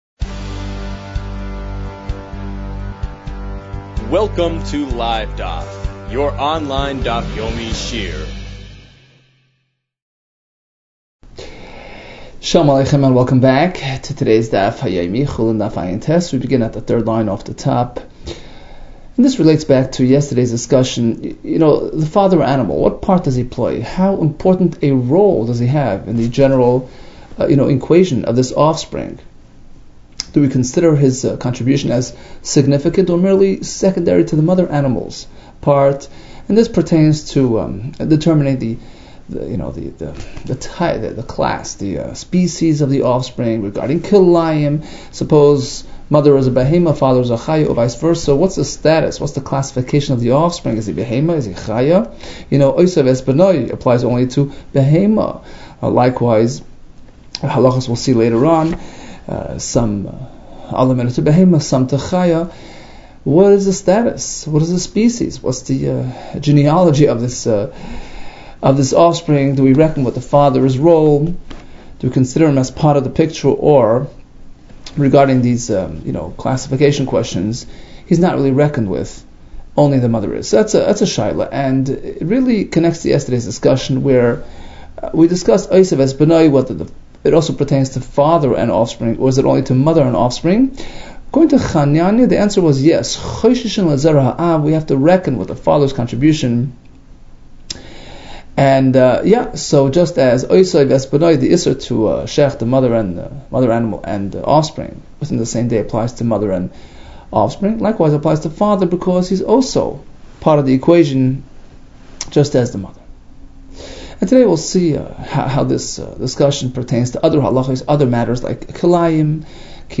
Chulin 79 - חולין עט | Daf Yomi Online Shiur | Livedaf